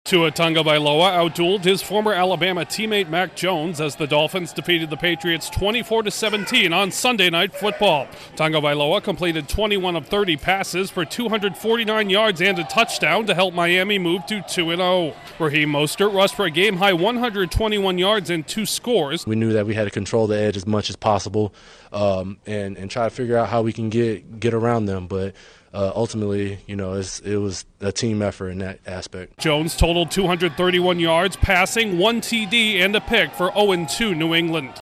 The Dolphins win a matchup of Crimson Tide signal-callers. Correspondent